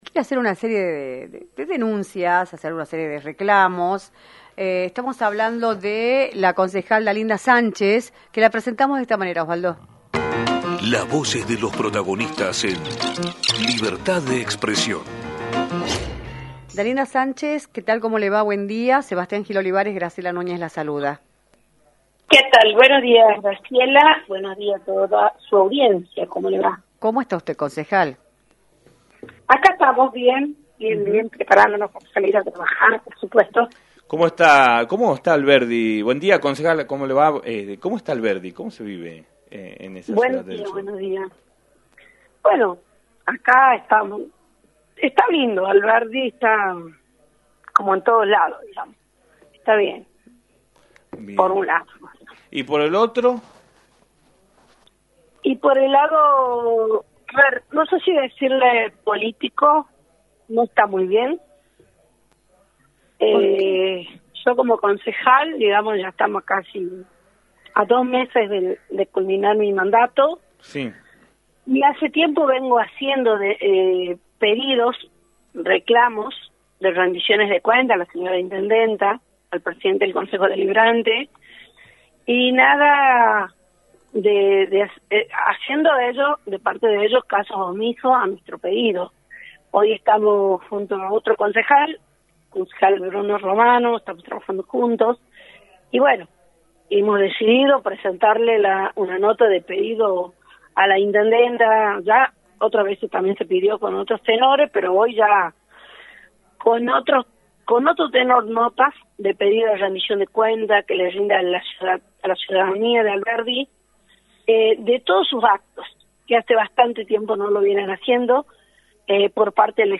Dalinda Sánchez, Concejal de la Municipalidad Alberdi, informó en «Libertad de Expresión», por la 106.9, en qué consisten las denuncias políticas que realizó ante las autoridades.